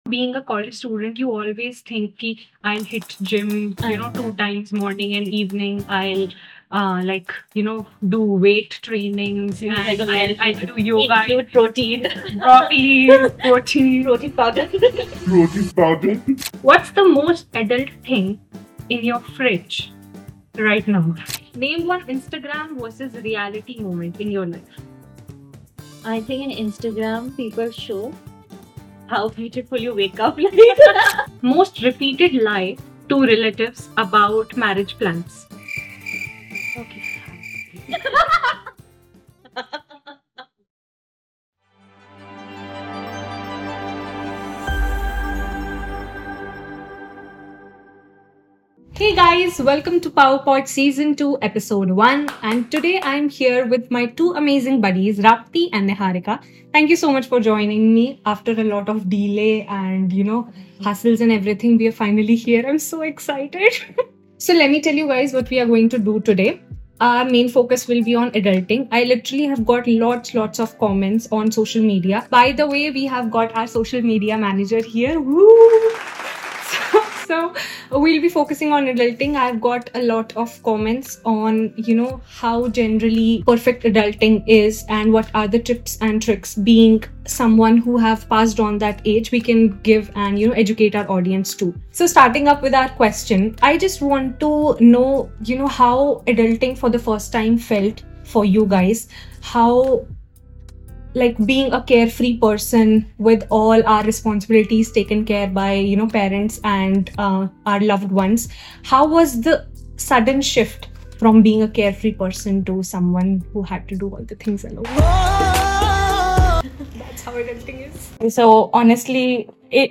Just real talk, useful tips, and a few laughs that make you feel less alone.